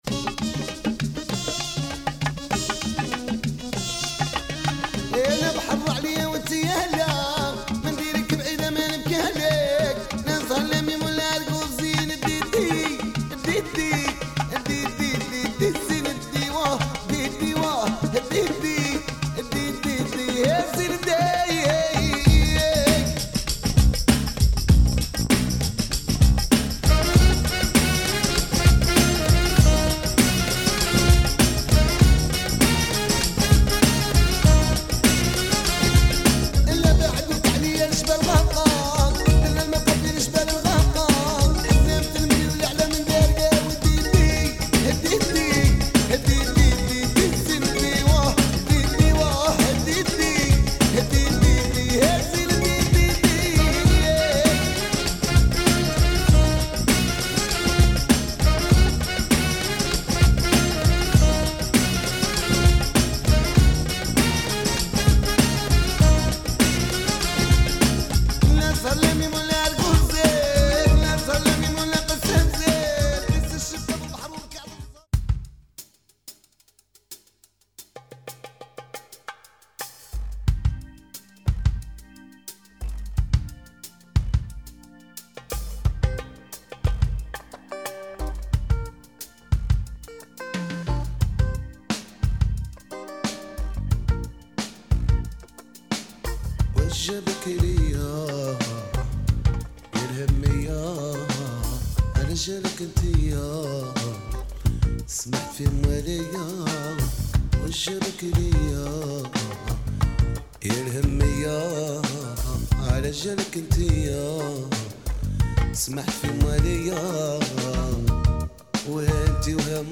Electronic raï